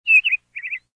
SZ_TC_bird3.ogg